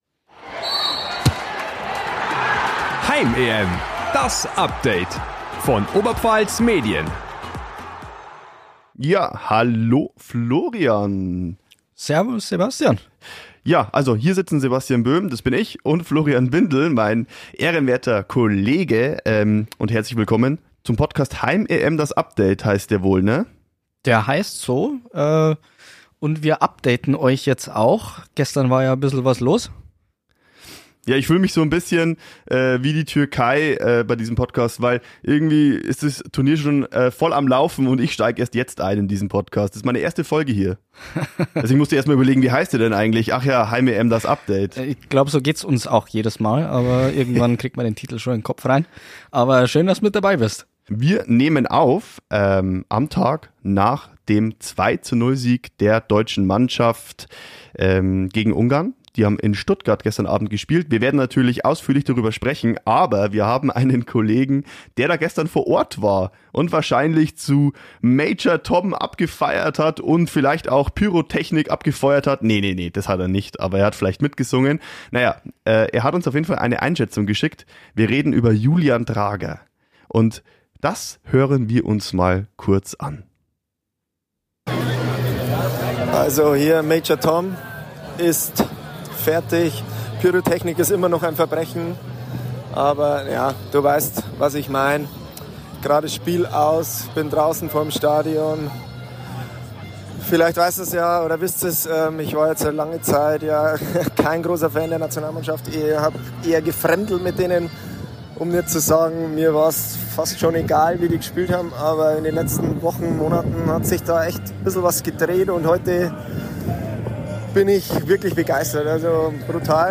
Er war im Stadion und hat zwei hochseriöse Sprachnachrichten abgesetzt.